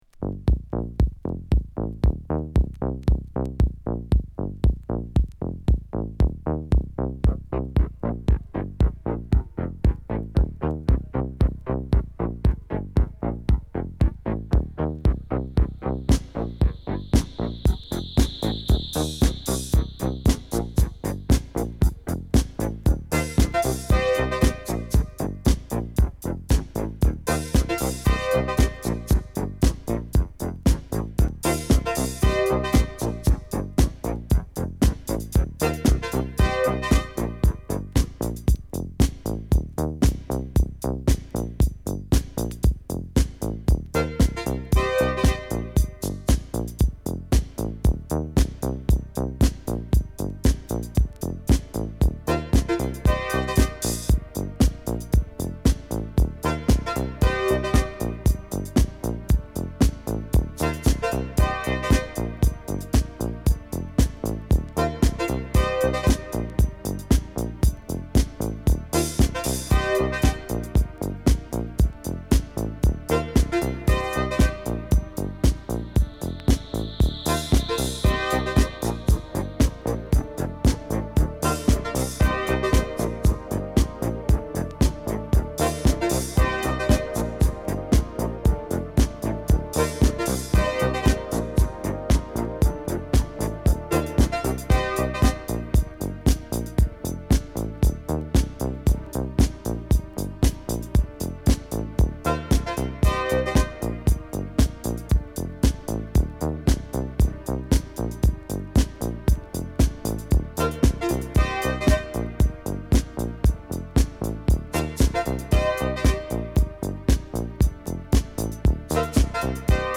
ガラージ〜ロフトクラシック！